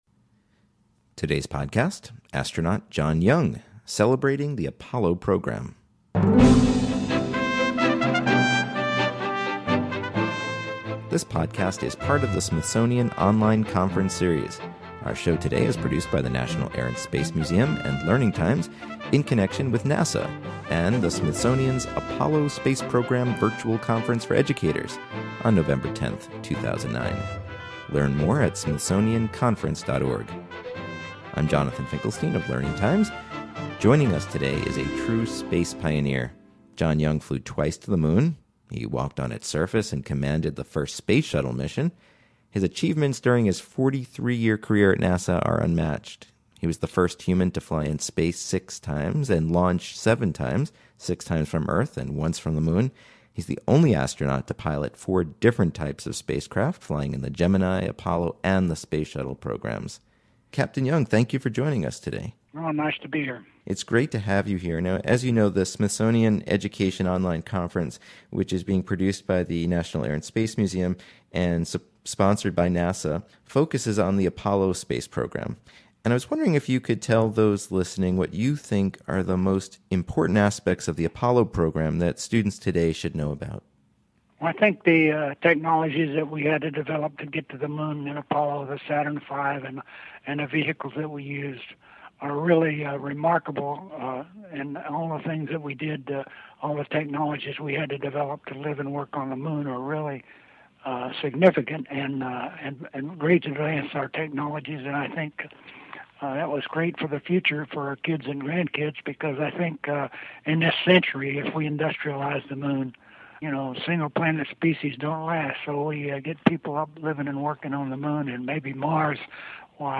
Listen in as Captain John Young talks about the Apollo program, his work on the moon, and his thoughts for the future of space exploration and manned missions to the moon and Mars.